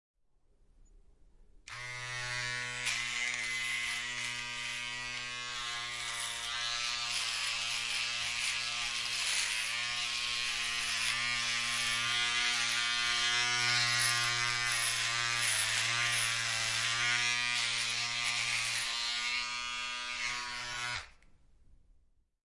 Download Razor sound effect for free.
Razor